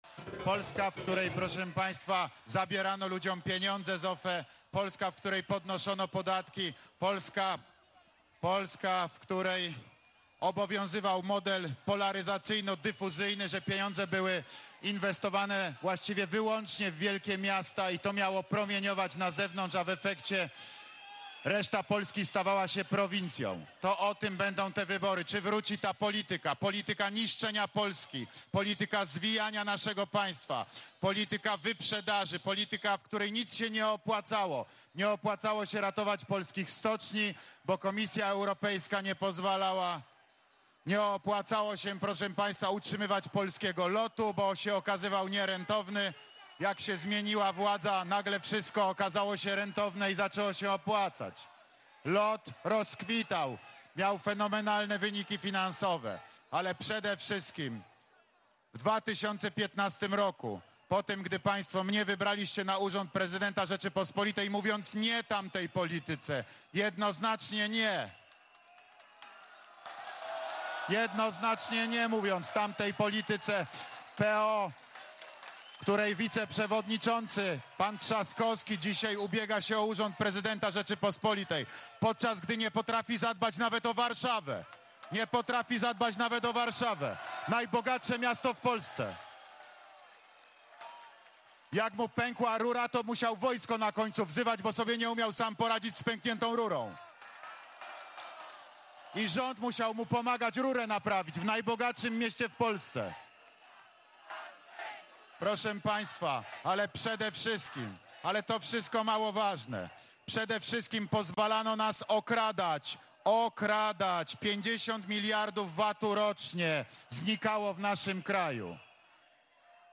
W czwartkowy wieczór odwiedził Nową Sól, gdzie wziął udział we wiecu z mieszkańcami regionu.
Posłuchaj wystąpienia prezydenta Andrzeja Dudy: